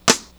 RIM9.WAV